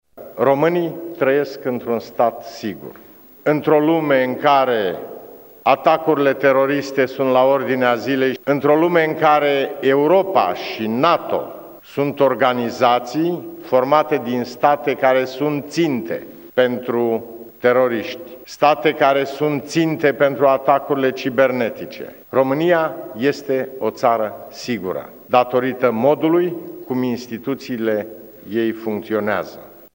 Aşa a declarat preşedintele Traian Băsescu, la bilanţul celor două mandate ca şef al statului.
România e o ţară sigură datorită modului cum instituţiile ei funcţionează, a precizat preşedintele Băsescu: